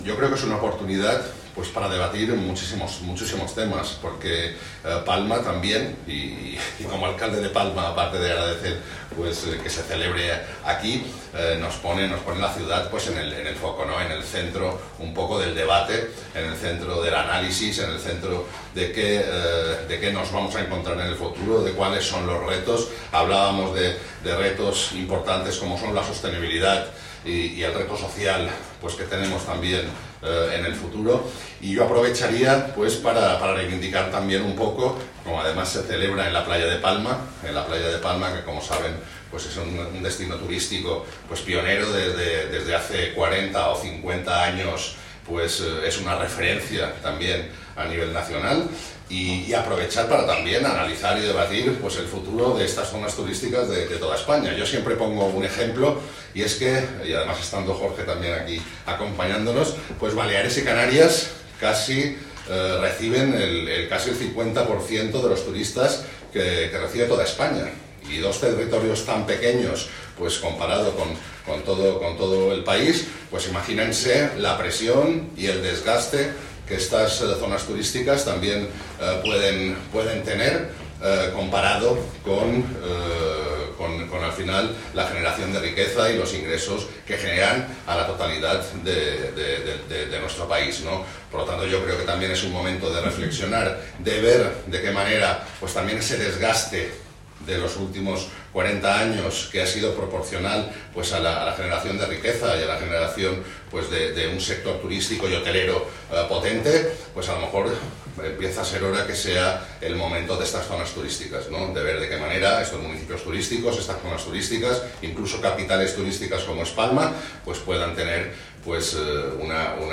22/11: ARRANCA EL CONGRESO CON LA RECEPCIÓN EN EL AYUNTAMIENTO DE PALMA
Declaraciones Jaime Martínez.mp3